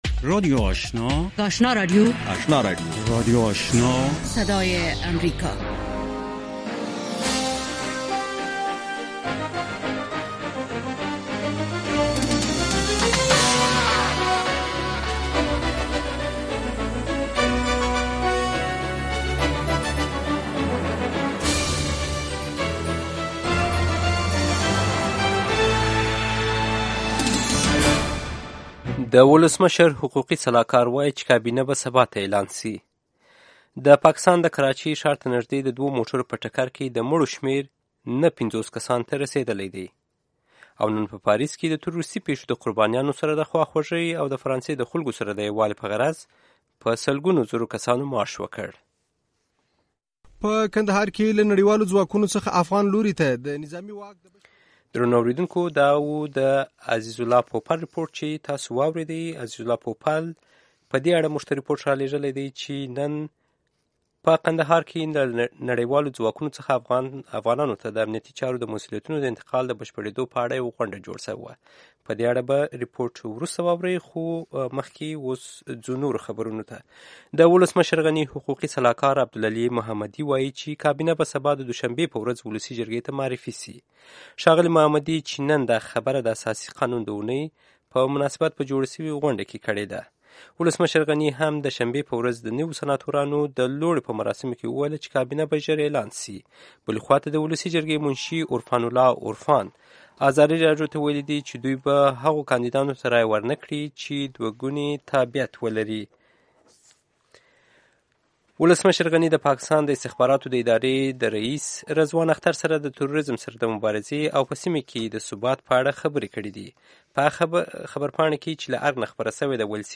یو ساعته پروگرام: تازه خبرونه، او د نن شپې تېر شوي پروگرامونه په ثبت شوي بڼه، هنري، علمي او ادبي مسایلو په اړه د شعر، ادب او بیلا بیلو هنرونو له وتلو څیرو سره.